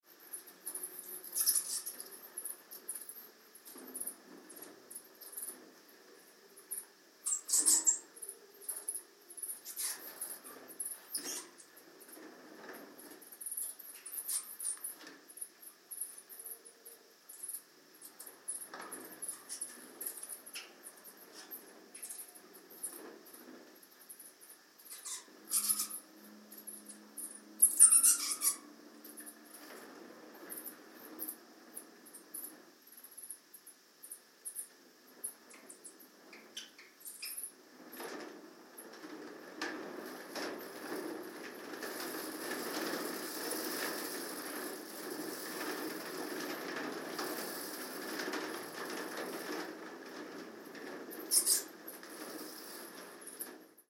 Bats at Ellora Cave No7
Bats inside Ellora Cave No7. Stereo 48khz 24bit.